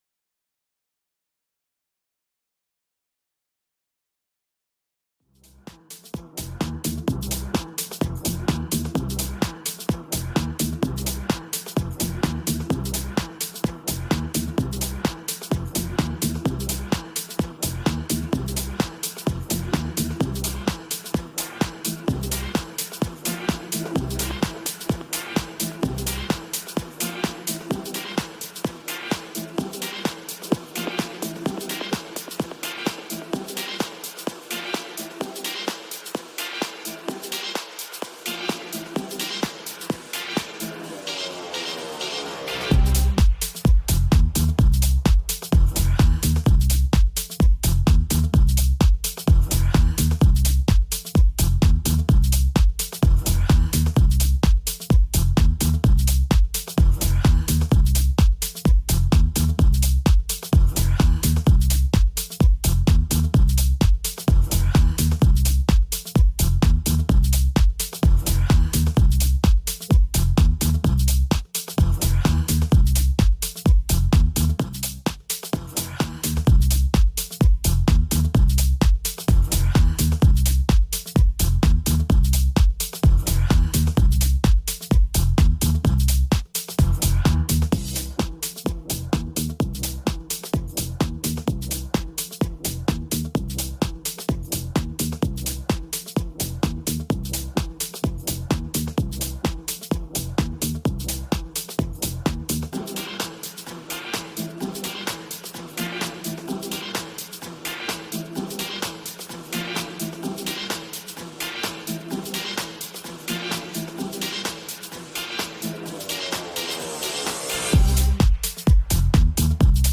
Also find other EDM Livesets, DJ Mixes